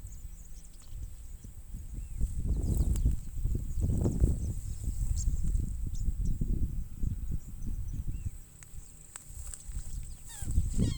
Fiofío Silbón (Elaenia albiceps)
Nombre en inglés: White-crested Elaenia
Localización detallada: Dique Río Hondo
Condición: Silvestre
Certeza: Vocalización Grabada
Fiofio-silbon.mp3